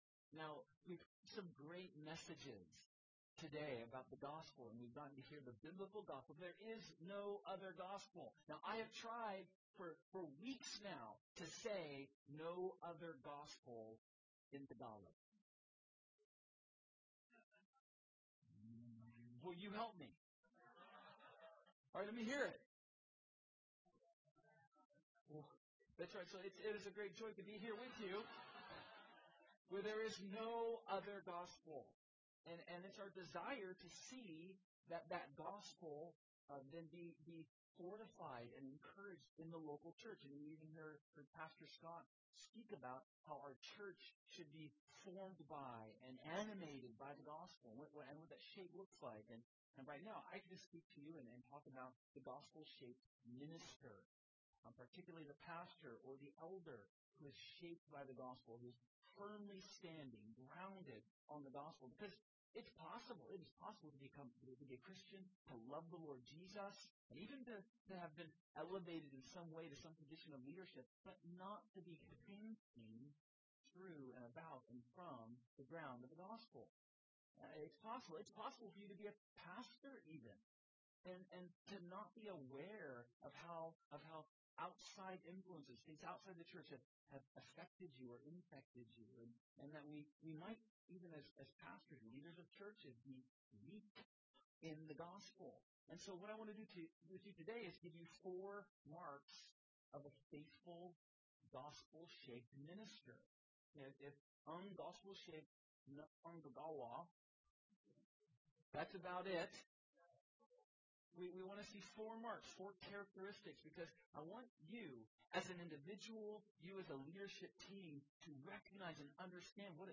A message from the series "Wala nang Ibang Gospel."